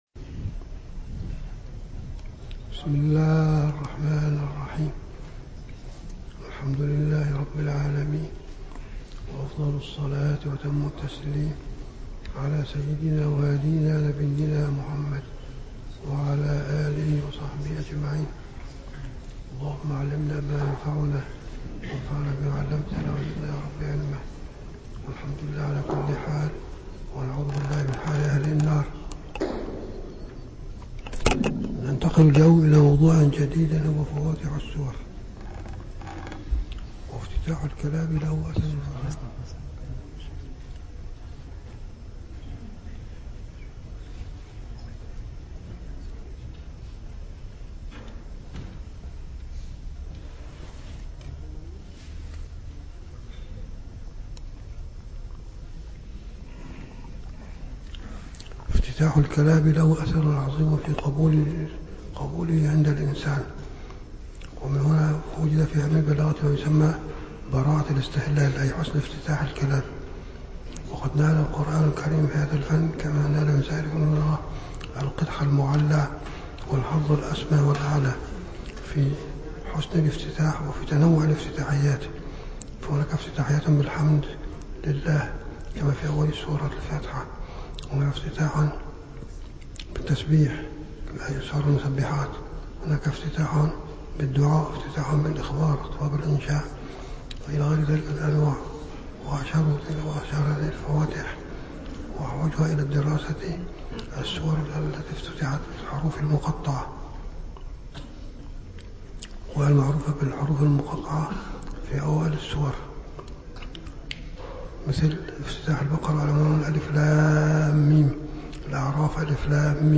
- الدروس العلمية - دورة مختصرة في علوم القرآن الكريم - 9- علوم القرآن الكريم